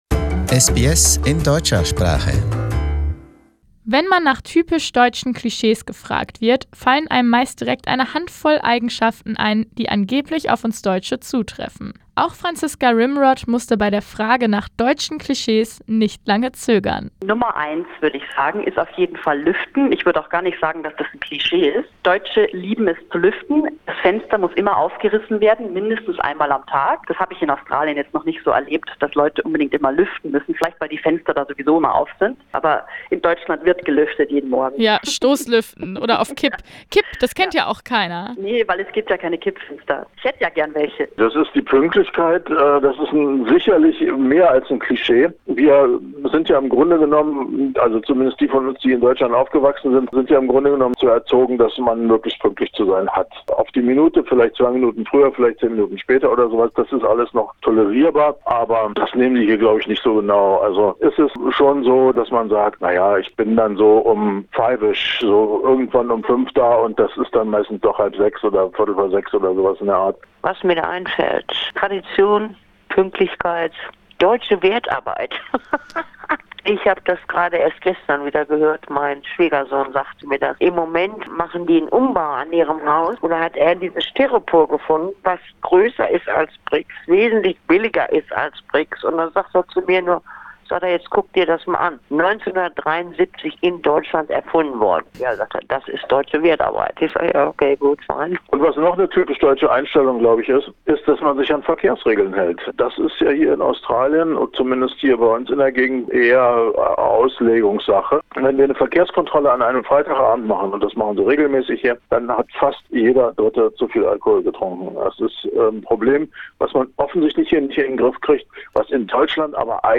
eine der Interviewpartner Source